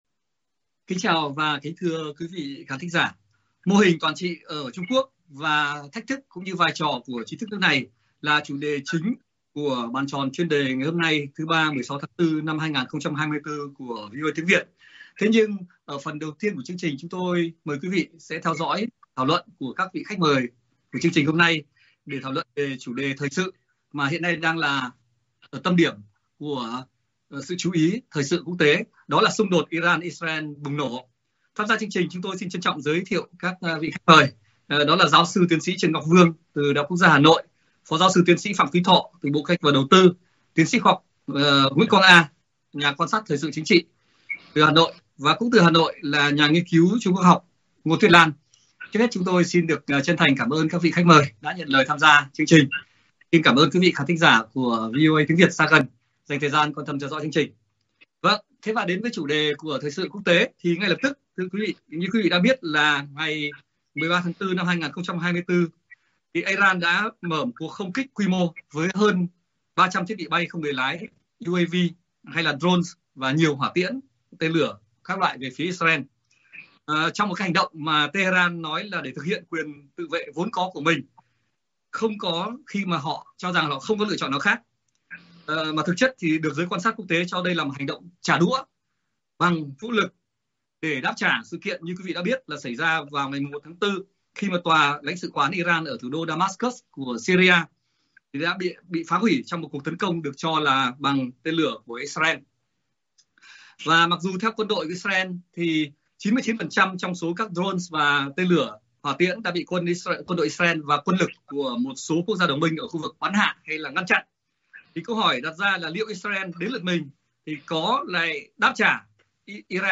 Các khách mời Bàn tròn Chuyên đề đề cập diễn biến đang là tâm điểm thời sự quốc tế đáng chú ý, và thảo luận mô hình thể chế, chế độ được cho là toàn trị ở Trung Quốc, cùng vai trò, ứng xử và thách thức của giới trí thức ở nước này.